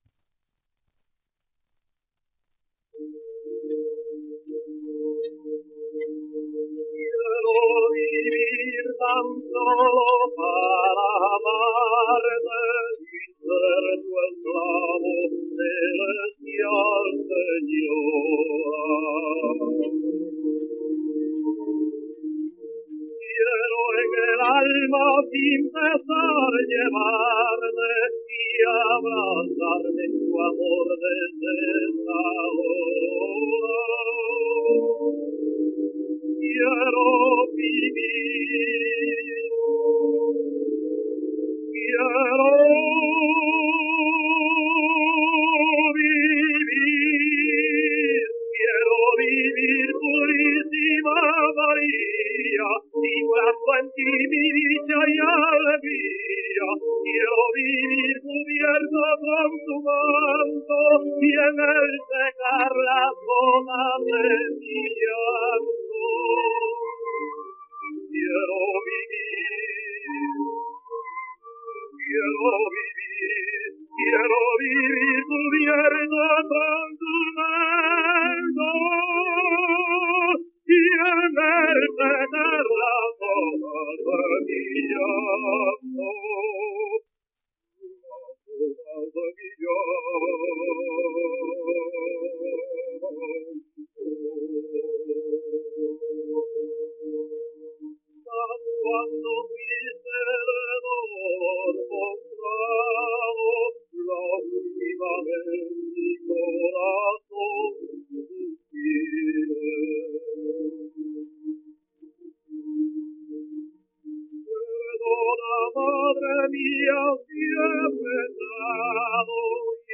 1 disco : 78 rpm ; 25 cm.